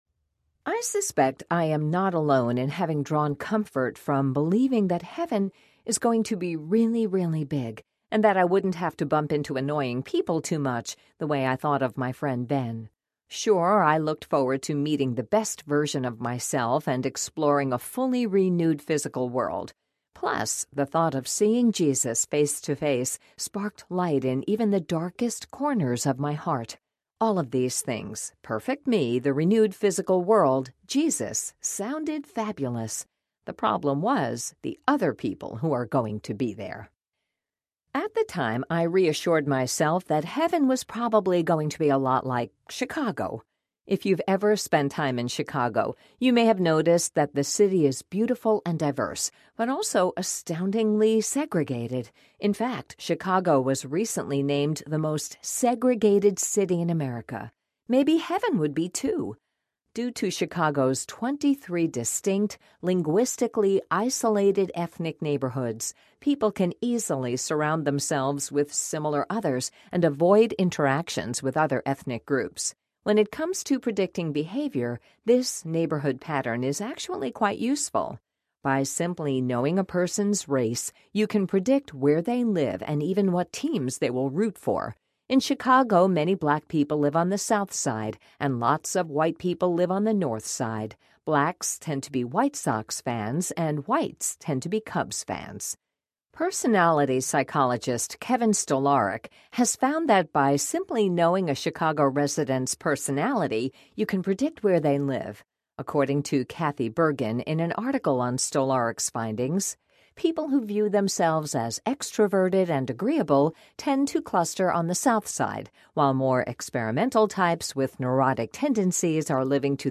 Disunity in Christ Audiobook
6.5 Hrs. – Unabridged